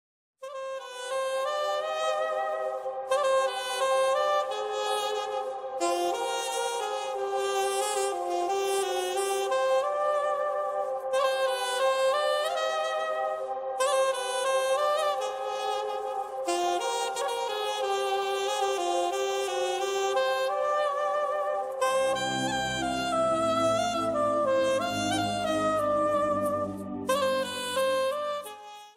Malayalam Ringtones